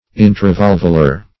Intravalvular \In`tra*valv"u*lar\, a.